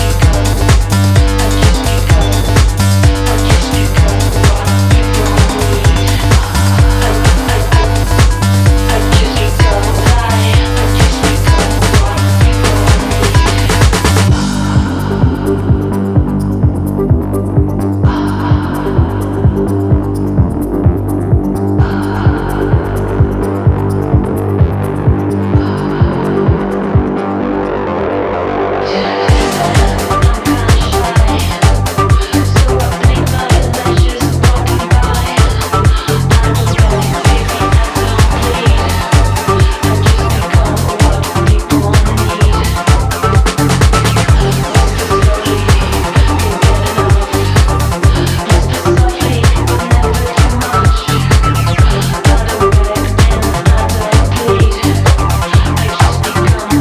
electronic duo